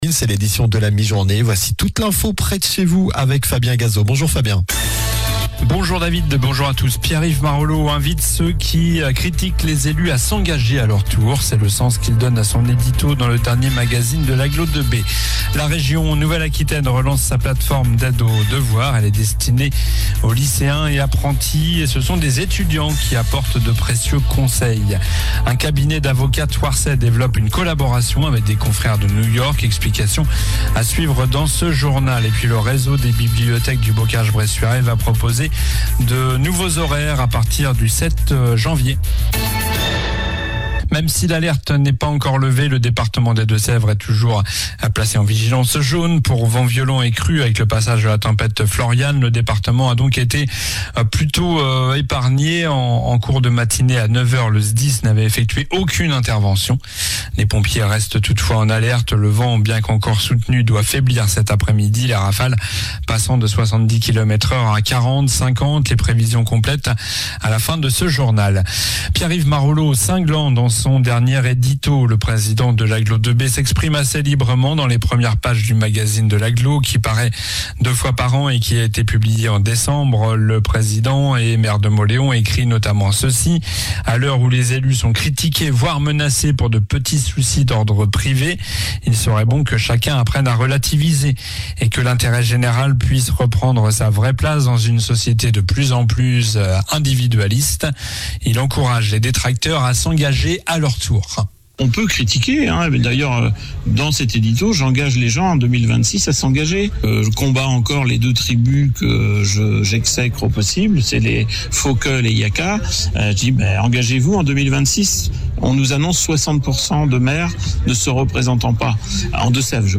Journal du lundi 06 janvier (midi)